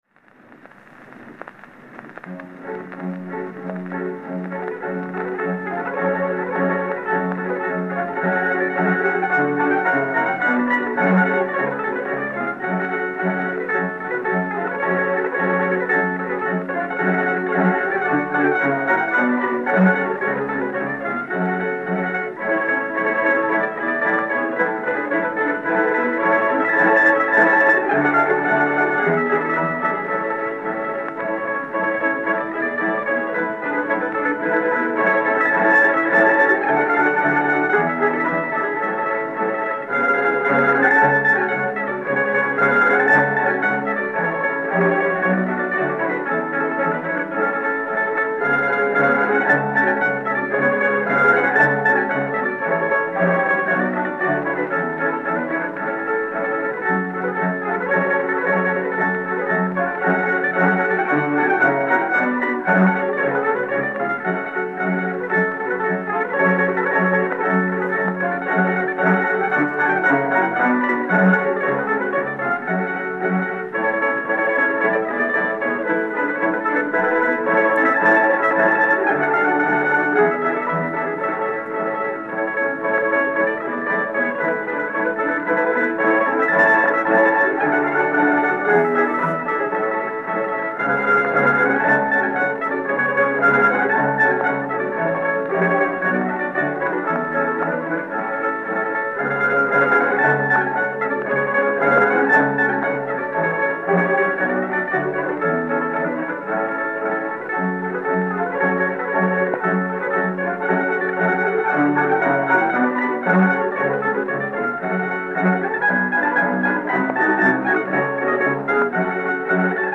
The 78’s have been recorded with microphones placed at the end of the horn of the player, (with no edit or cleaning up ).
UNKNOWN RUSSIAN BALALAIKA ORCHESTRA
schubert-balalaika-orch.mp3